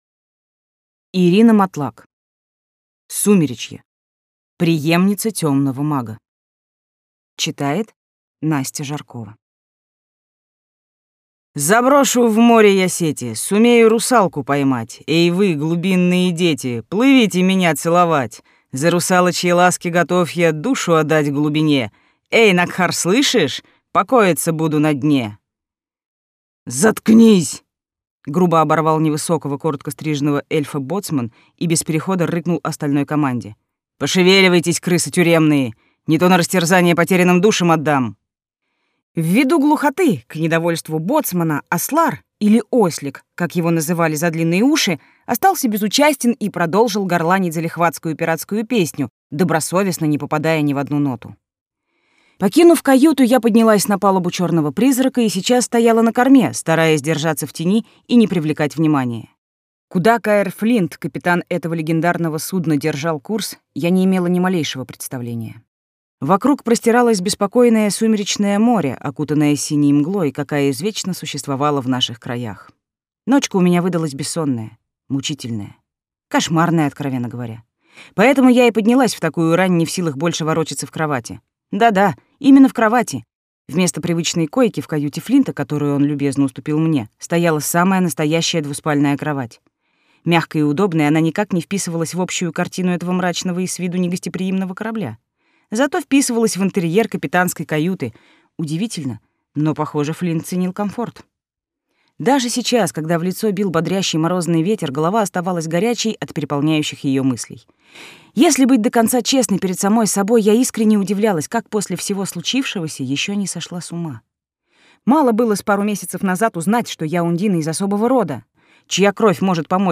Аудиокнига Сумеречье. Преемница темного мага | Библиотека аудиокниг